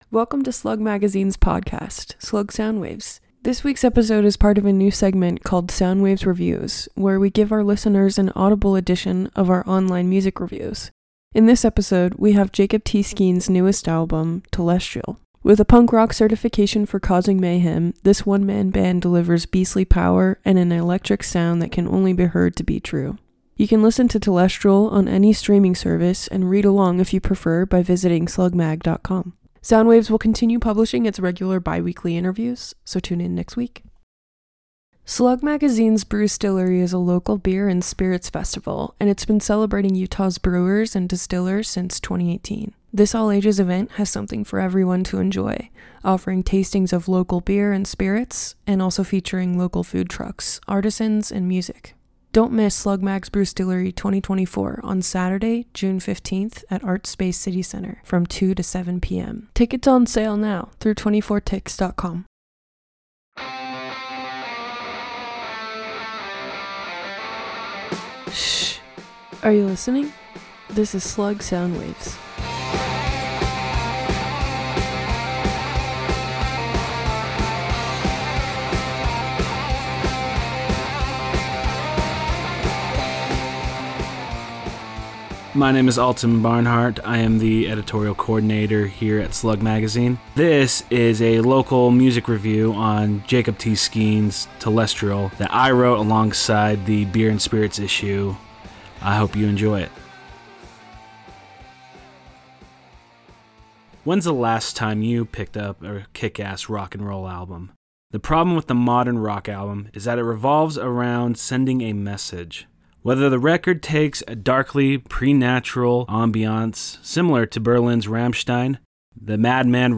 This week’s episode is part of a new segment called Soundwaves Reviews, where we give our listeners an audible edition of our online music reviews.